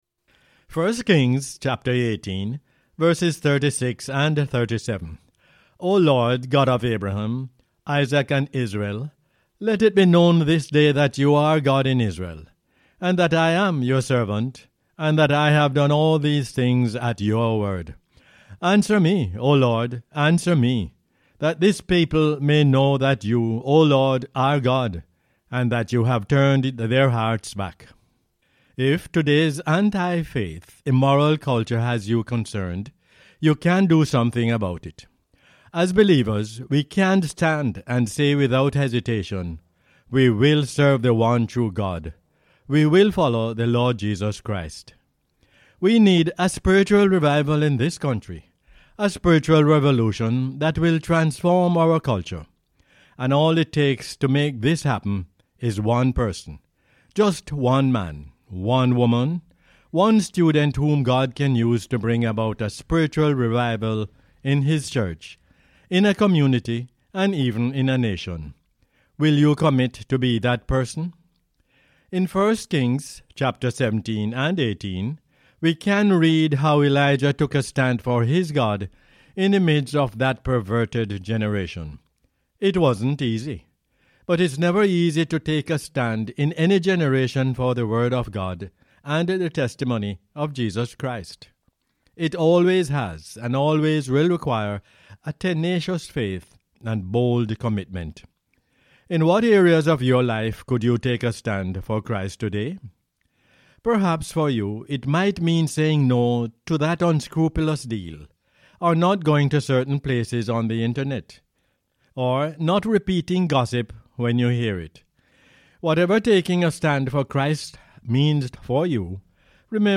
1 Kings 18:36-37 is the "Word For Jamaica" as aired on the radio on 4 November 2022.